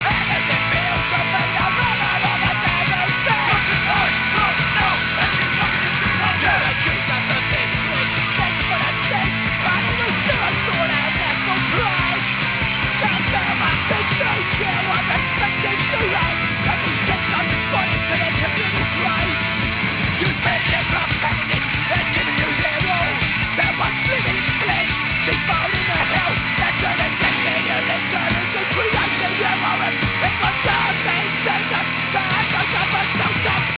Mono excerpt